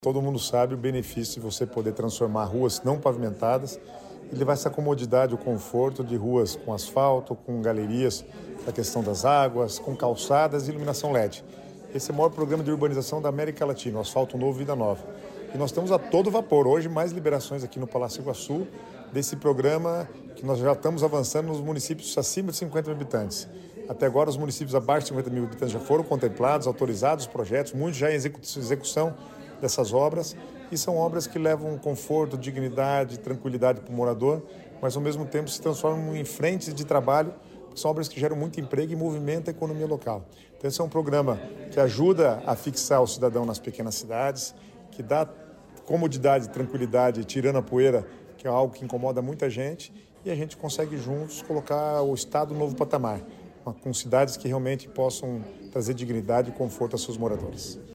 Sonora do secretário das Cidades, Guto Silva, sobre a liberação do programa Asfalto Novo, Vida Nova